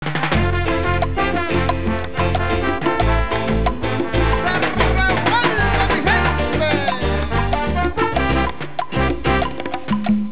music.au